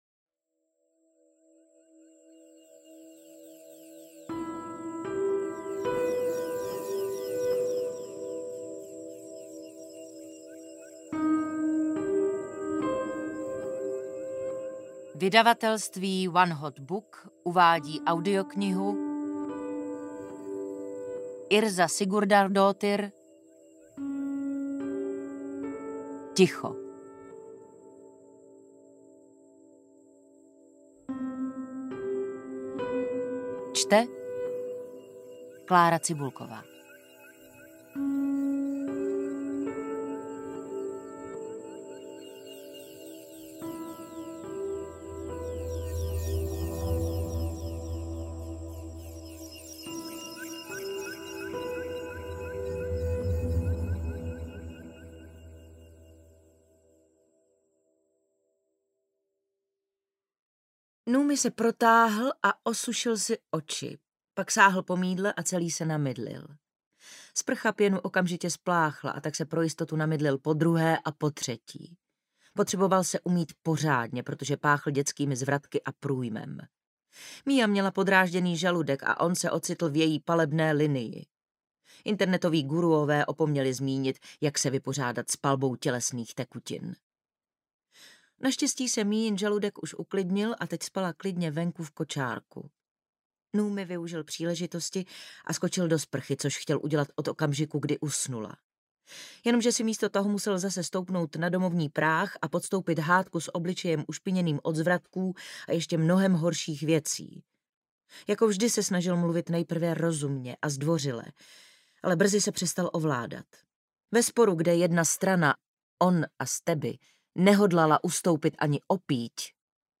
Ticho audiokniha
Ukázka z knihy
• InterpretKlára Cibulková